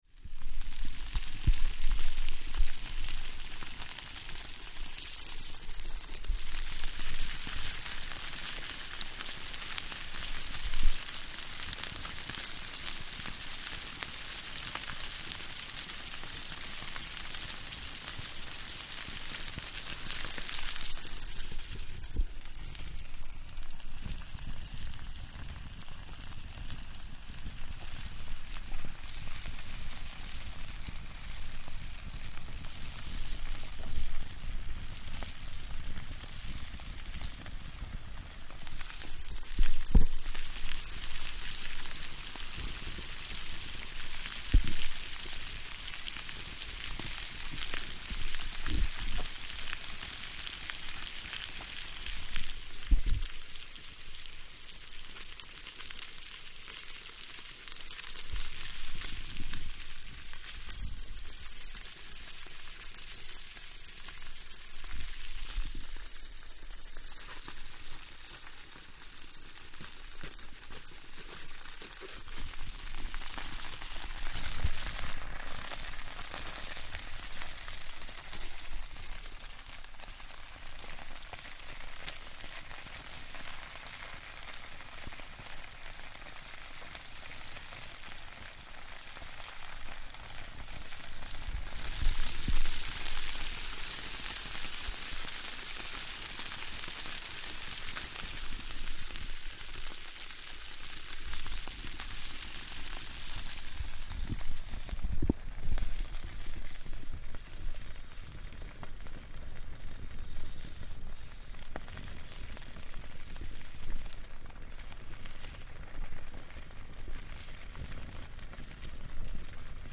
Cooking on an open fire
Cooking potatoes wrapped in foil, and sausage in a pan on an open wood fire while camping in the Brecon Beacons national park in Wales. In this recording you can clearly hear the fire flickering and licking the wood while the food cooks.